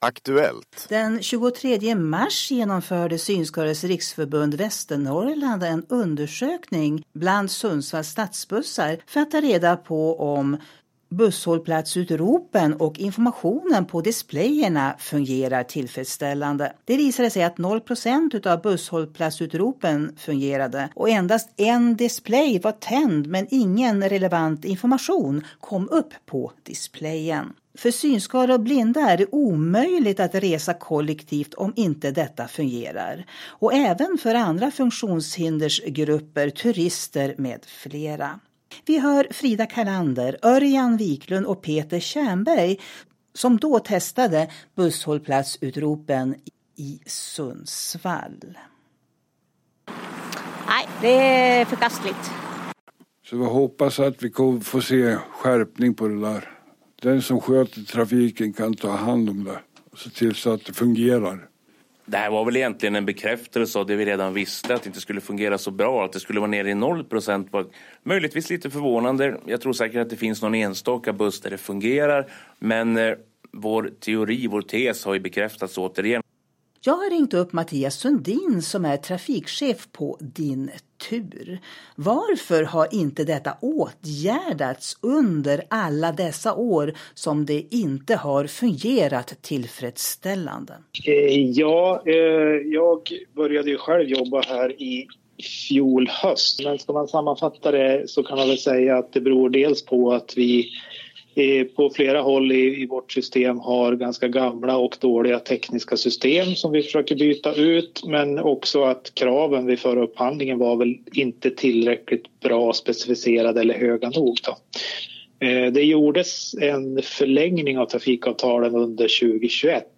intervjuas om busshållsplatsutrop och tillgänglighet i det kollektiva resandet som DinTur erbjuder länsborna. Just nu testas ny teknik på bussarna och om några veckor ska nyheten lanseras i Västernorrland.